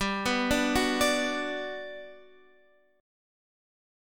Bm/G chord